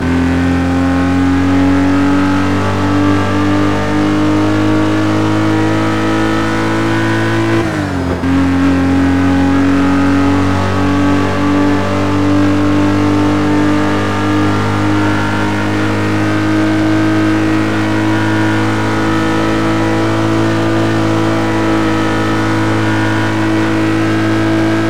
Index of /server/sound/vehicles/lwcars/lotus_esprit
fourth_cruise.wav